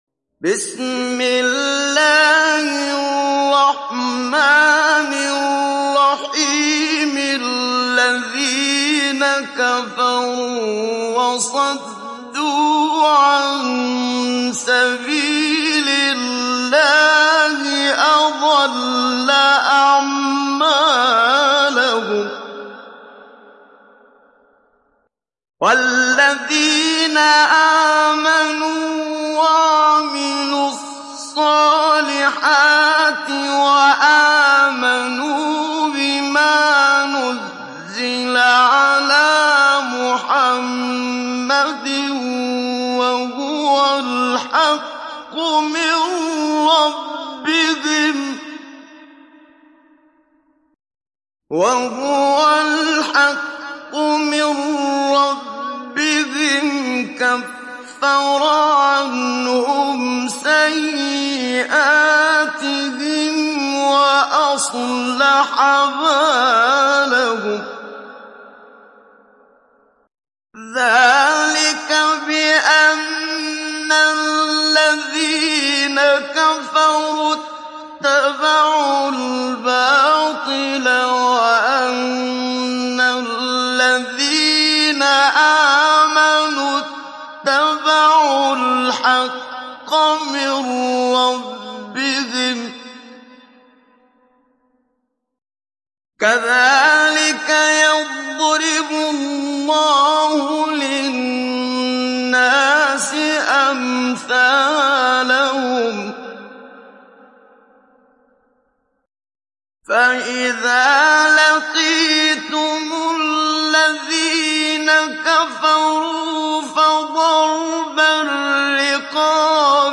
Download Surah Muhammad Muhammad Siddiq Minshawi Mujawwad